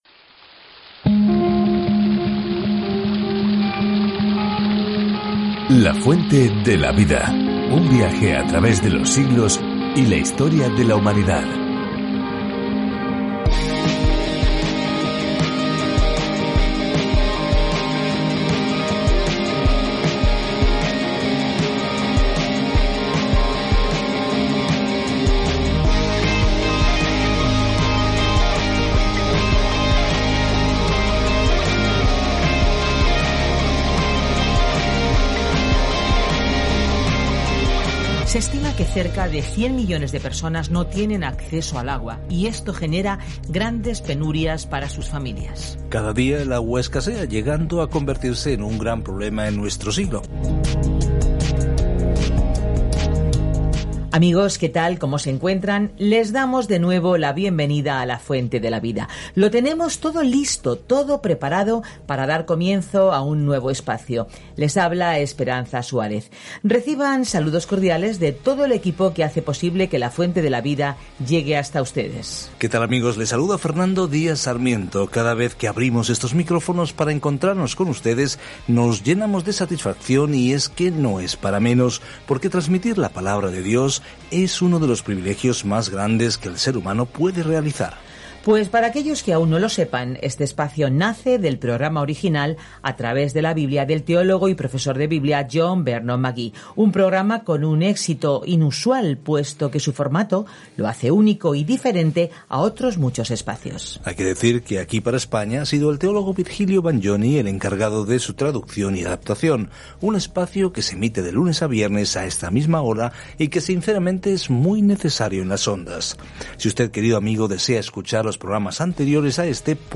Escritura GÉNESIS 1:2-25 Día 1 Iniciar plan Día 3 Acerca de este Plan Aquí es donde comienza todo: el universo, el sol y la luna, las personas, las relaciones, el pecado, todo. Viaja diariamente a través de Génesis mientras escuchas el estudio de audio y lees versículos seleccionados de la palabra de Dios.